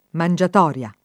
mangiatoria [ man J at 0 r L a ] s. f.